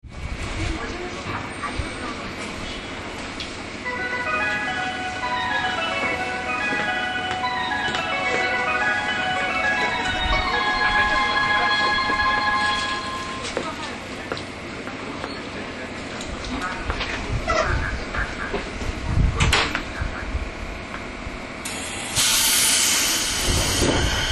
走行音
TK05 211系 真鶴→根府川 5:17 9/10 上の続きです。